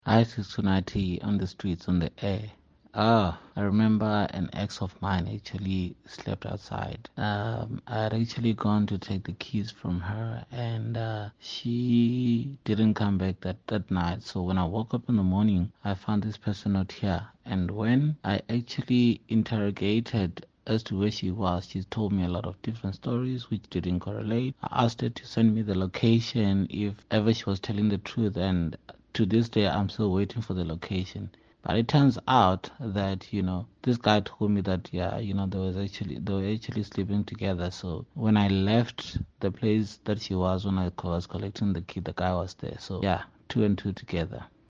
Listen to Midday Joy listeners sharing their stories with Unathi: